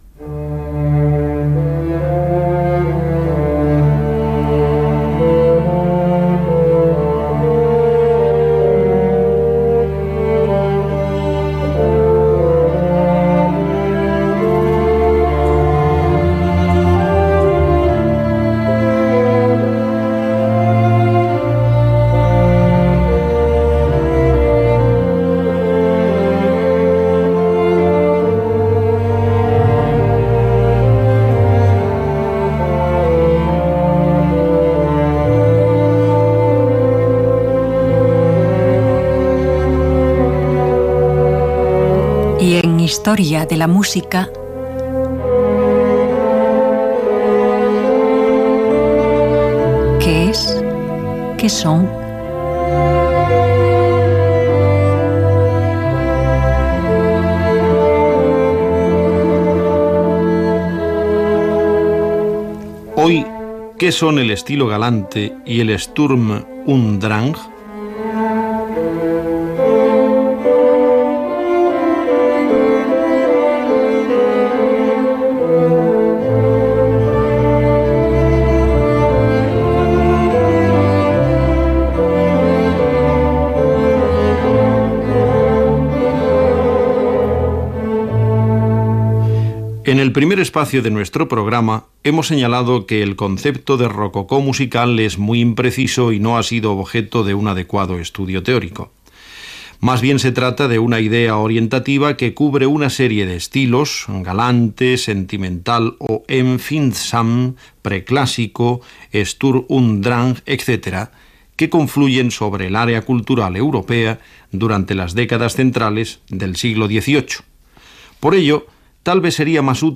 Secció "Qué es? Qué son?" amb una explicació de l'estil galant i tema musical
Musical